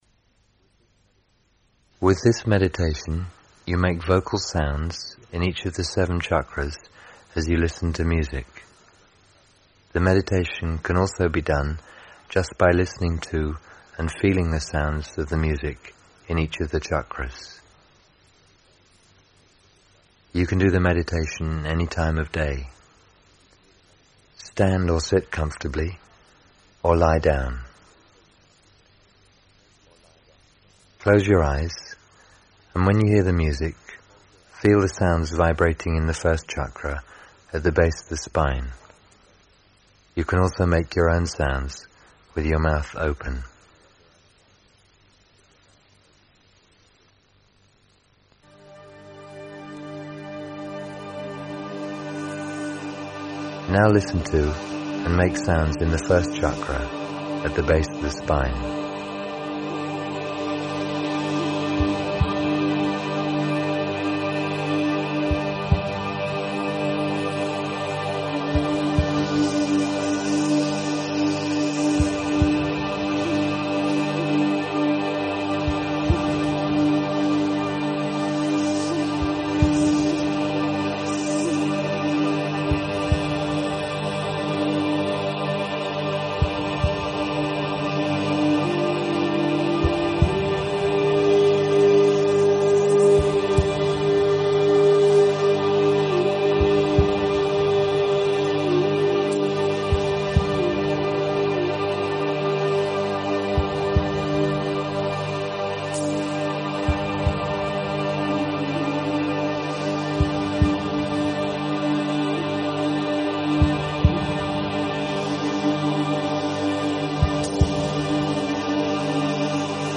Chakra Breathing Meditation is a practice that brings awareness and energy to each of the seven chakras. This active meditation uses rapid breathing and gentle movement to revitalize the chakras, accompanied by music for a guided experience.